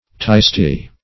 Search Result for " tystie" : The Collaborative International Dictionary of English v.0.48: Tystie \Ty"stie\, n. [Cf. Icel.